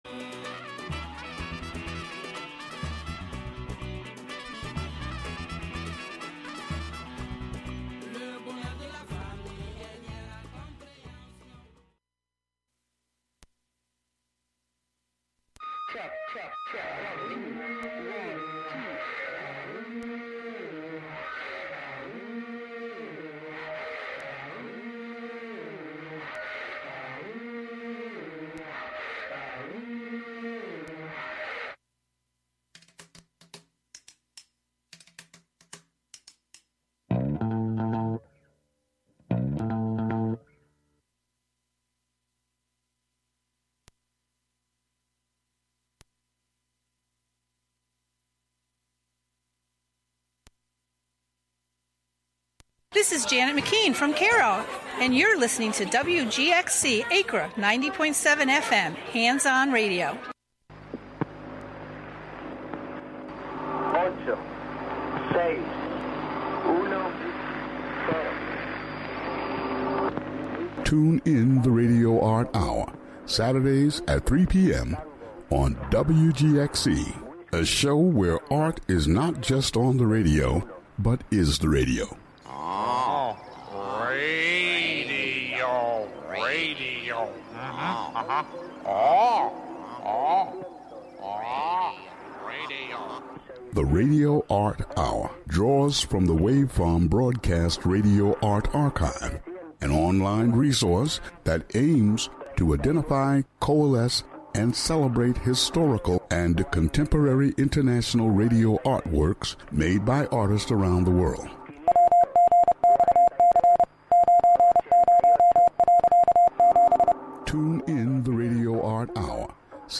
Folk-rock music